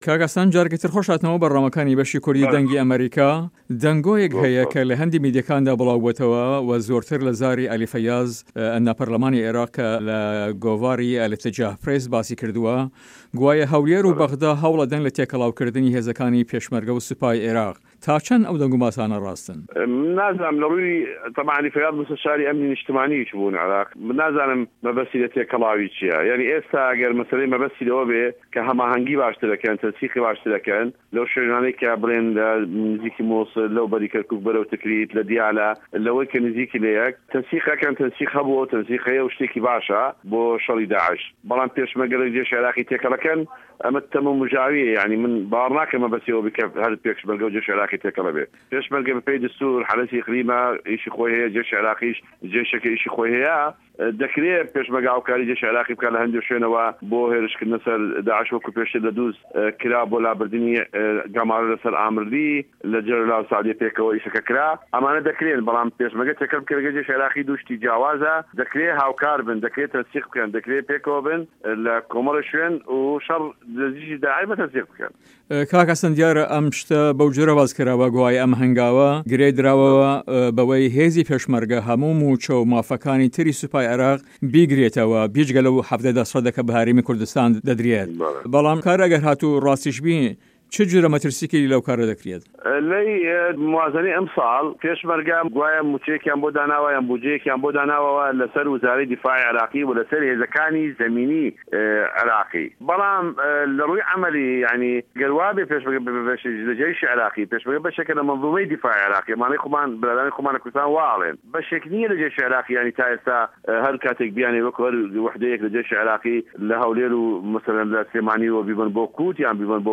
وتو وێژ له‌گه‌ڵ حه‌سه‌ن جیهاد